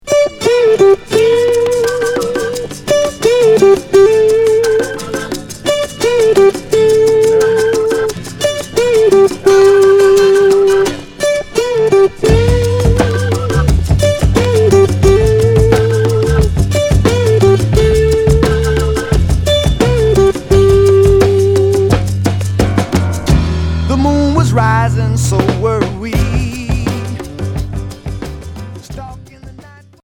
Pop west coast Unique 45t retour à l'accueil